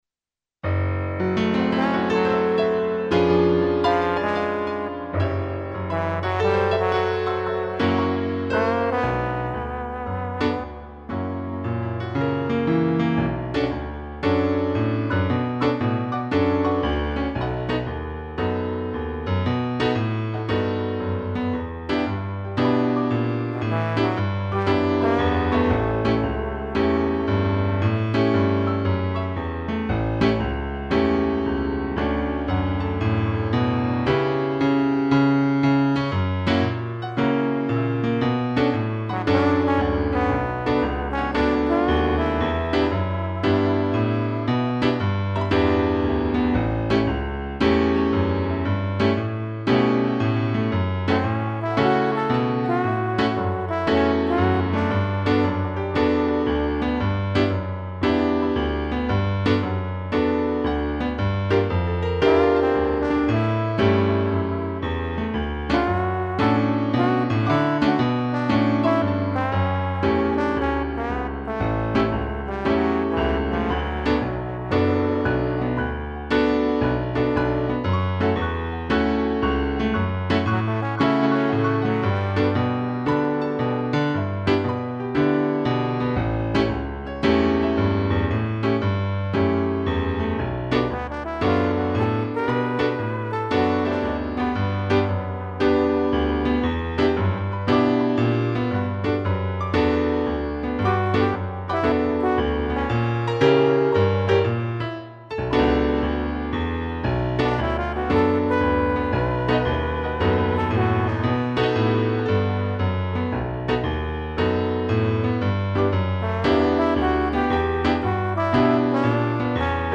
piano e trombone
(instrumental)